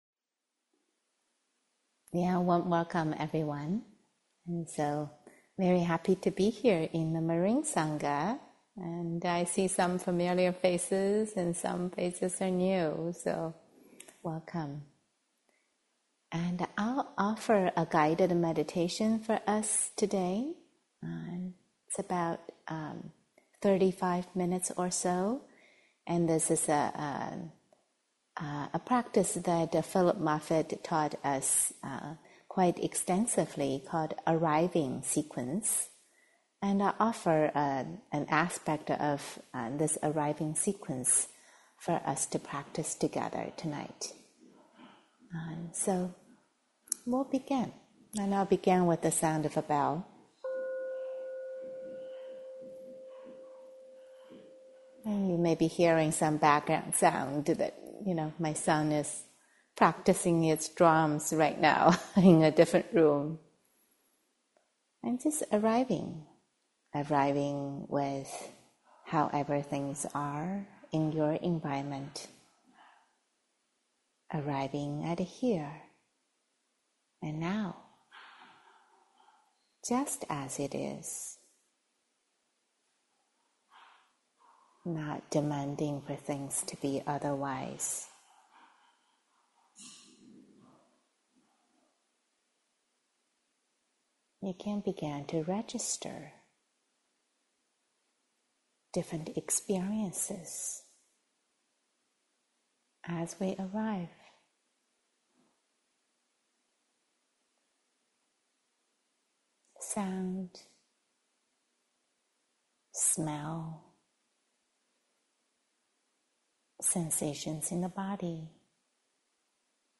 The Tyranny of Expectations (Guided Meditation and Dharma Talk